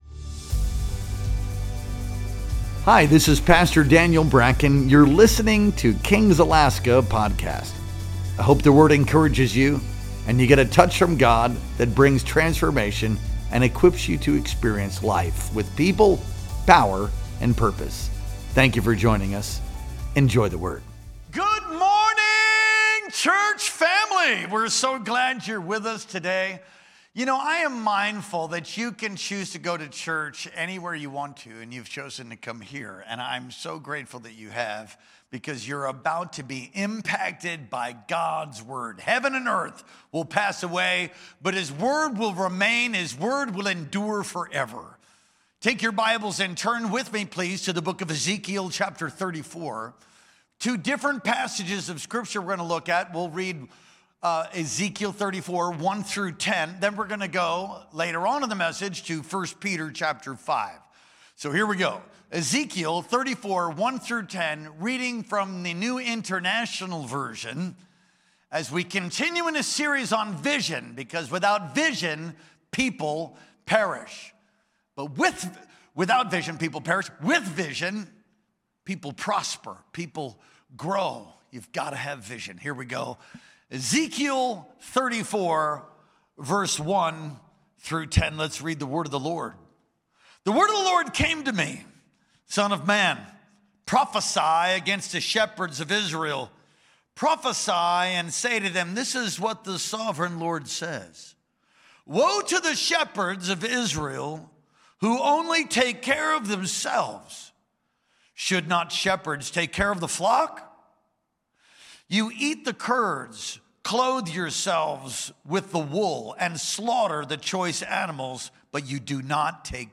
Our Sunday Morning Worship Experience streamed live on November 16th, 2025.